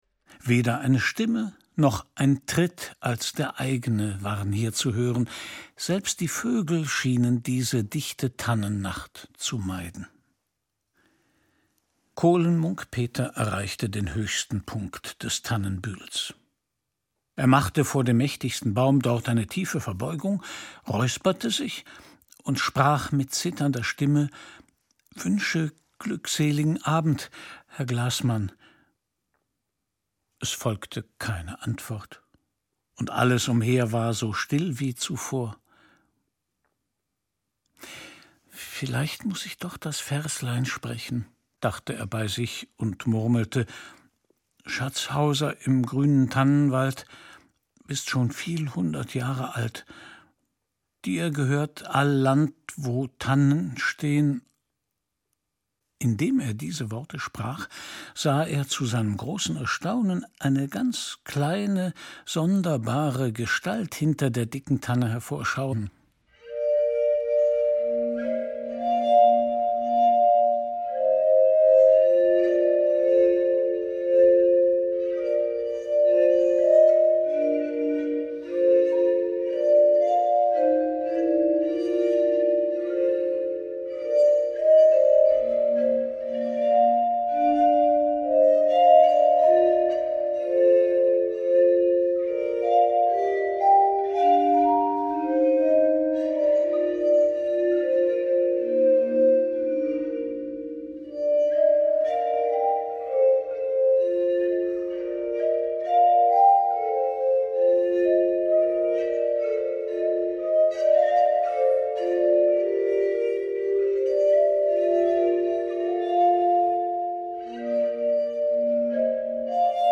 Klarinetten
Fagott
Glasharfe und Schlagwerk
Erzähler: Christian Brückner